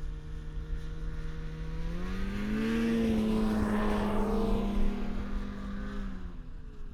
Snowmobile Description Form (PDF)
Subjective Noise Event Audio File (WAV)